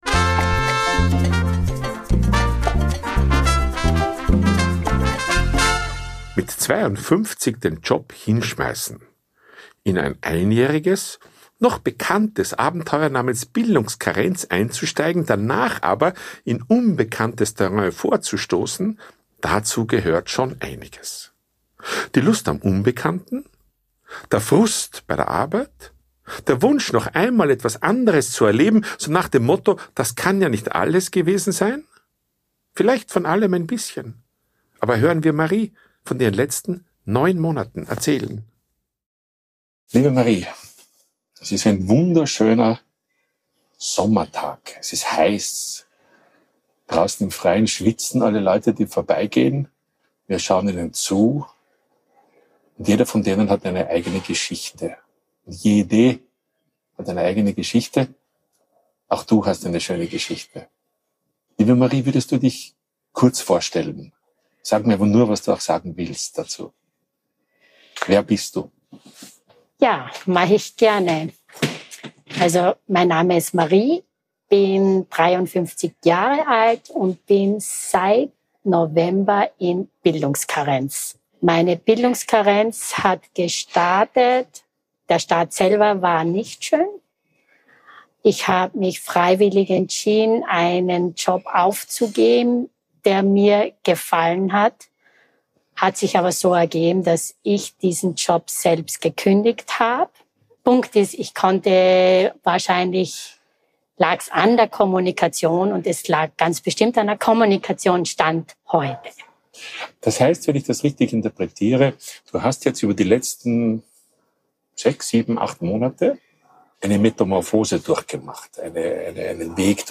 Wie lange sie in welcher Phase zubrachte, wie sie sich weiterentwickelt hat, und was ihre Zukunftspläne sind, verrät sich mir heute, bei einem Kaffee im schönen Café Kaiserfeld.